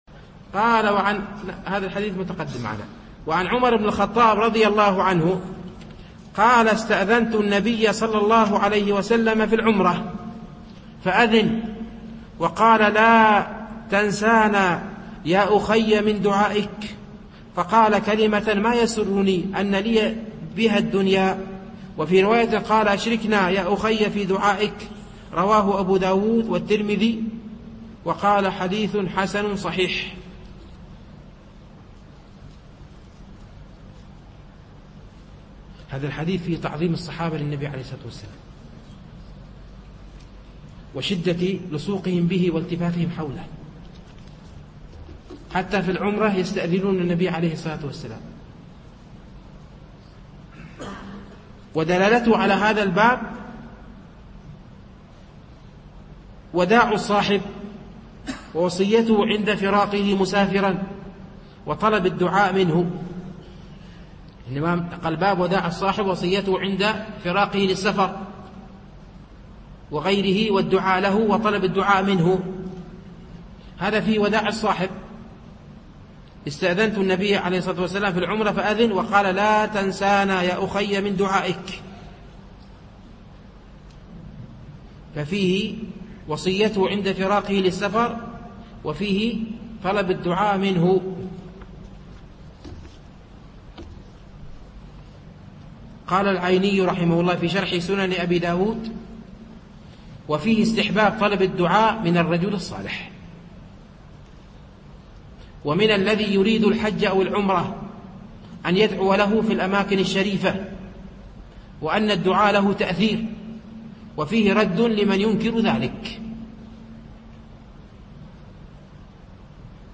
عن عمر بن الخطاب رضي الله عنه قال استأذنت النبي صلى الله عليه وسلم في العمرة فأذن وقال: لا تنسانا يا أخي من دعائك فقال كلمة ما يسرني أن لي بها الدنيا وفي رواية قال: أشركنا يا أخي في دعائك. الألبوم: شبكة بينونة للعلوم الشرعية المدة: 7:47 دقائق (1.82 م.بايت) التنسيق: MP3 Mono 22kHz 32Kbps (VBR)